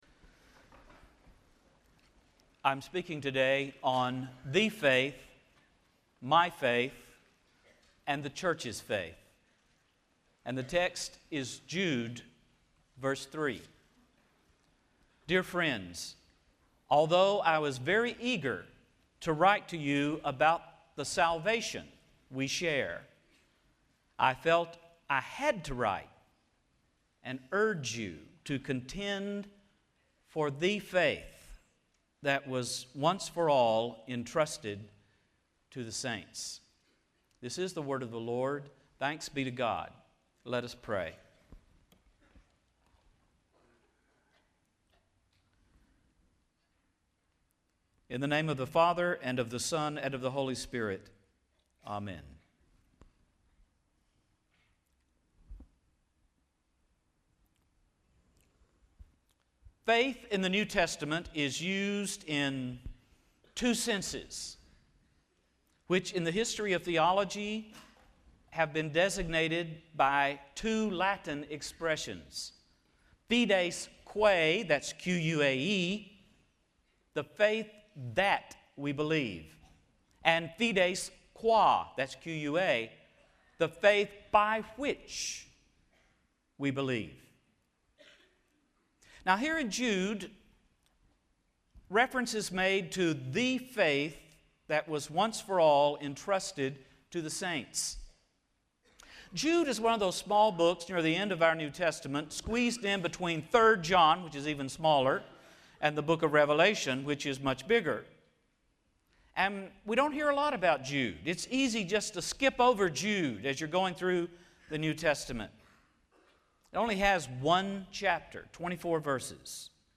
Future of Denominationalism Conference
AL Address: Baptists and Their Relations with Other Christians Recording Date